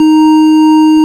Index of /90_sSampleCDs/Keyboards of The 60's and 70's - CD1/ORG_FarfisaCombo/ORG_FarfisaCombo
ORG_VIP Bch  D#4.wav